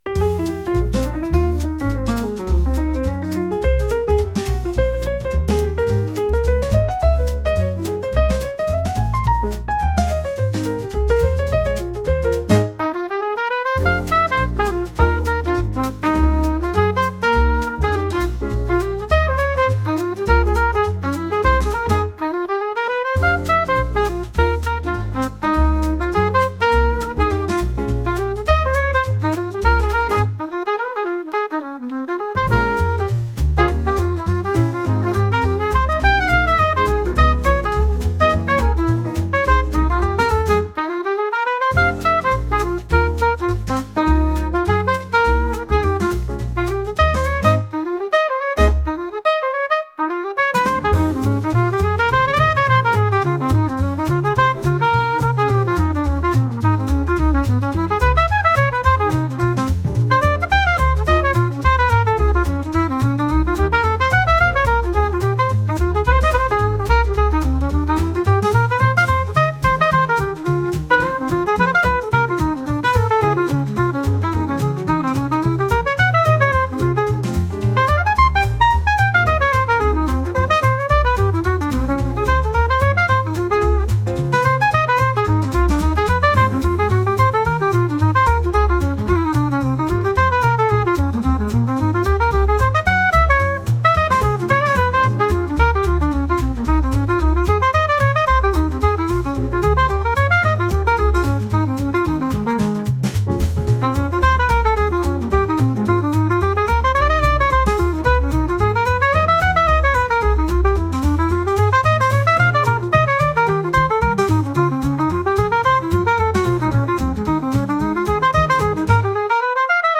落ち着いた感じのジャズ曲です。